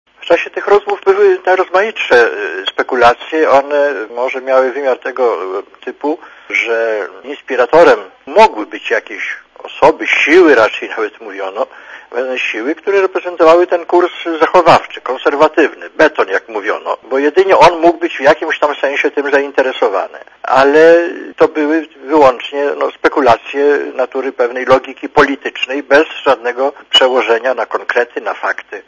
Posłuchaj komentarza gen. Jaruzelskiego